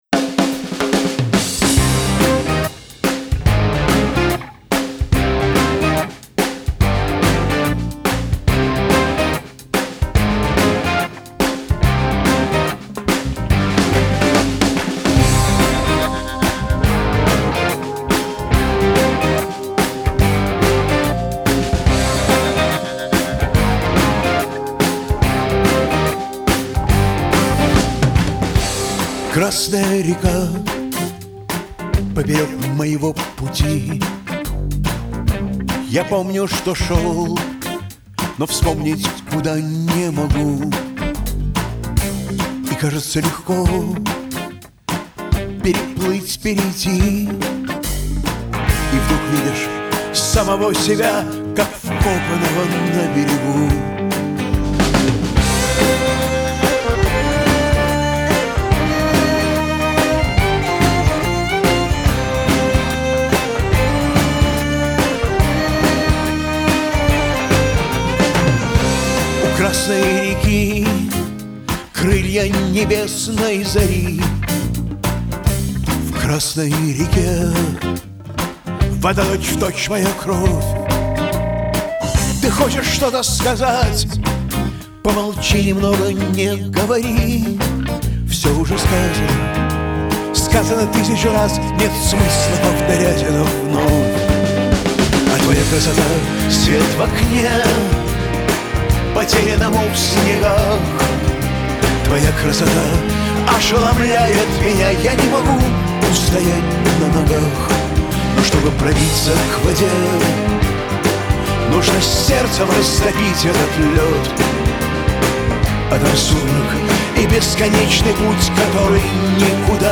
Genre: Rock